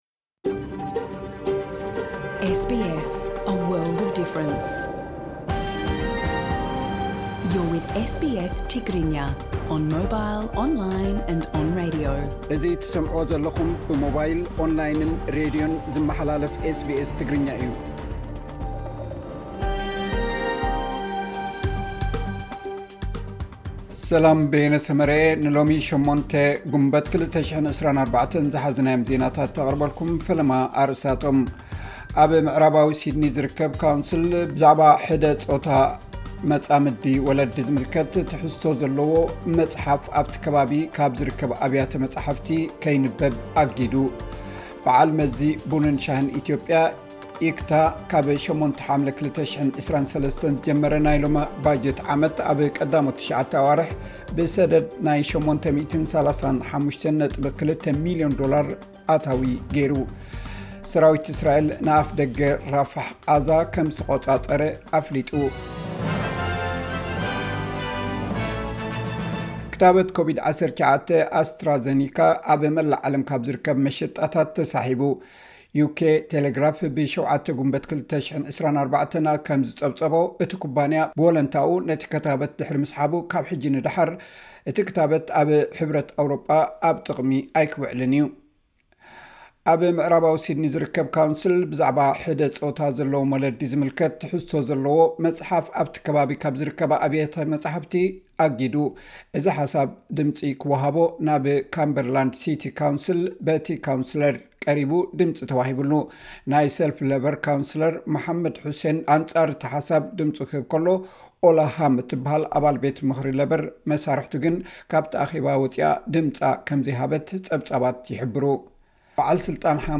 ሓጸርቲ ዜናታት ኤስ ቢ ኤስ ትግርኛ (08 ግንቦት 2024)